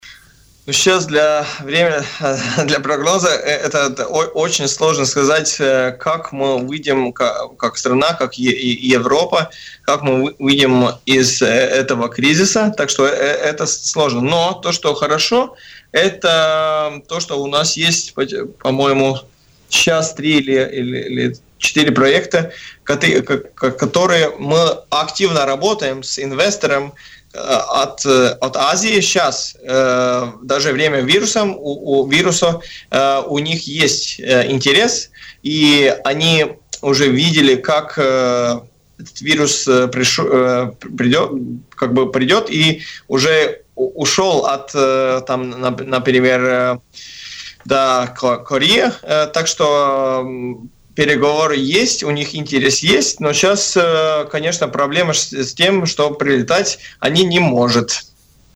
Инвесторы из Азии заинтересованы в проектах, над которыми в данный момент работает Латвия. Об этом в эфире радио Baltkom рассказал директор Латвийского агентства инвестиций и развития Каспарс Рожкалнс.